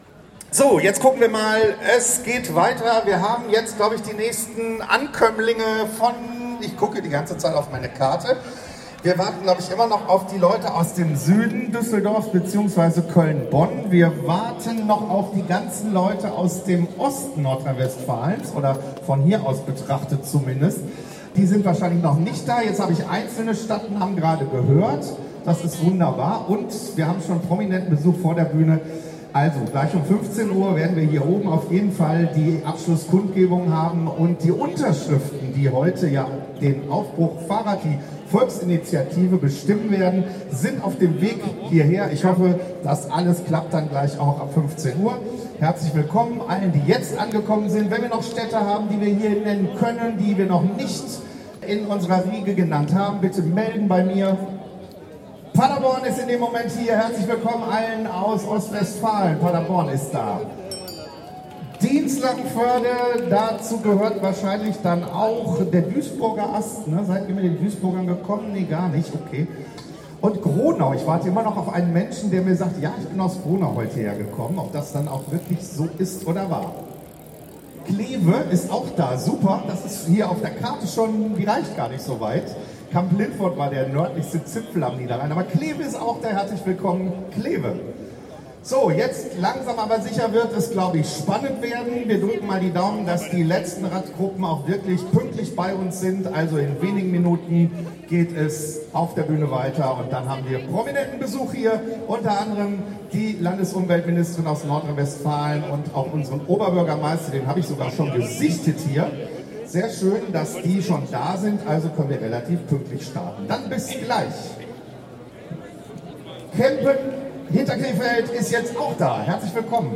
Kapitel 2: Kundgebung und Unterschriftenübergabe
Die Reden rund um das Anliegen der Volksinitiative „Aufbruch Fahrrad“